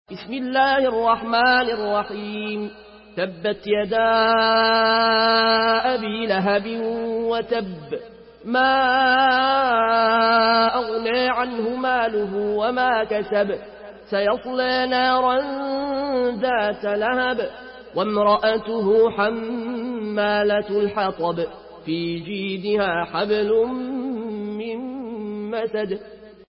Surah Tebbet MP3 by Al Ayoune Al Koshi in Warsh An Nafi From Al-Azraq way narration.
Murattal